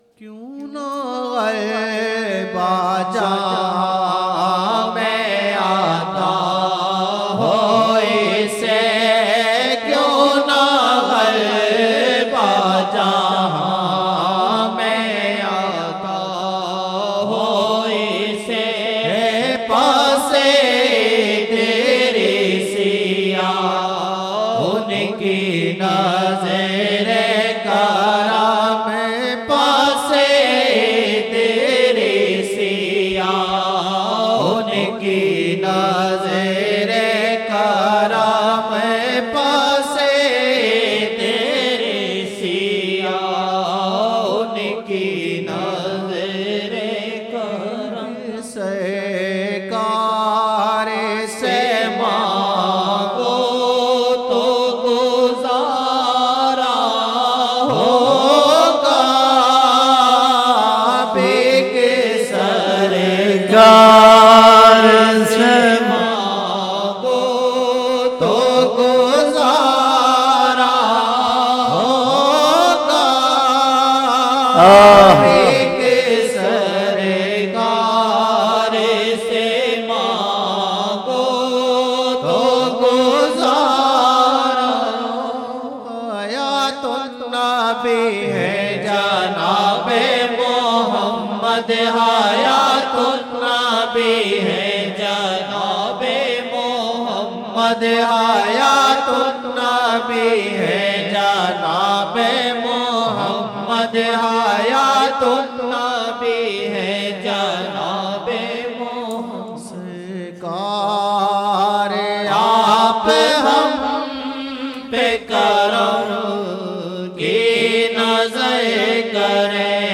Chand Naatia Ashar 2007-01-07 Asr 07 Jan 2007 Old Naat Shareef